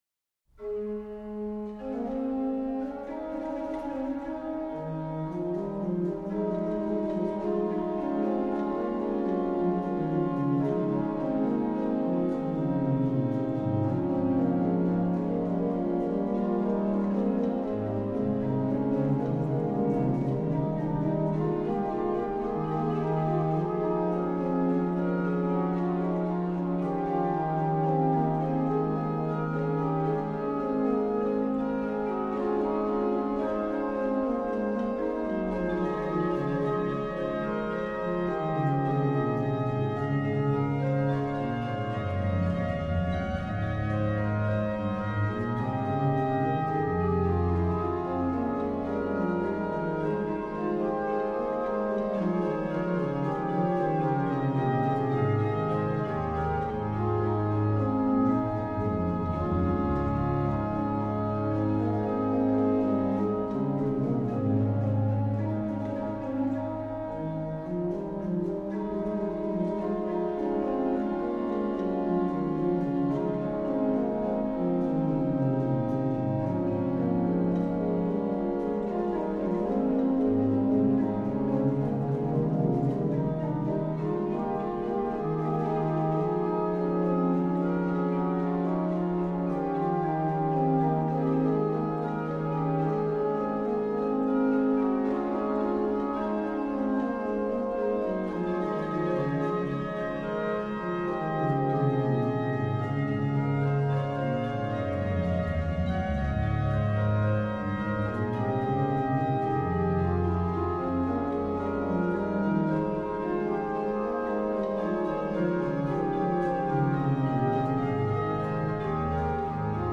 OW: Pr8, Ged8, Qnt8
BW: Ged8, Pr4, Oct2
HW: Pr8, Rfl8, Oct4, Oct2, Zim
Ped: Pr16, Oct8, Oct4, Pos16